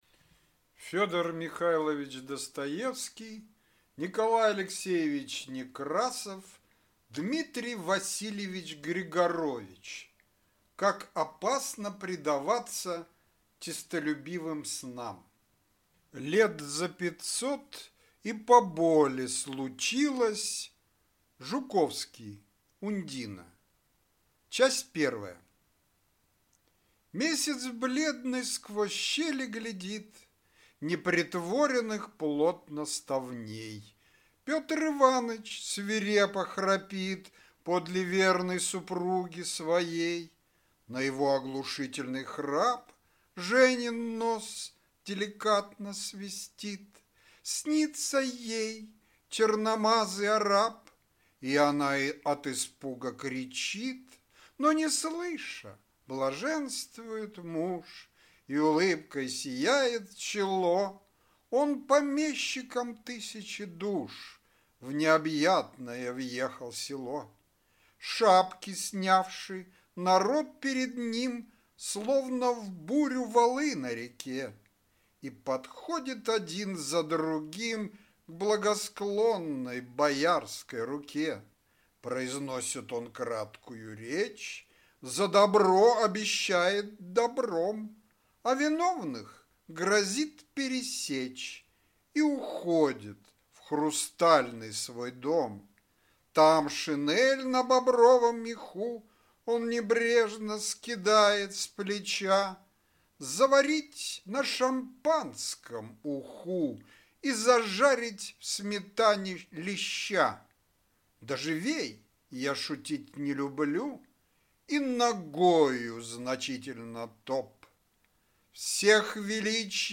Аудиокнига Как опасно предаваться честолюбивым снам | Библиотека аудиокниг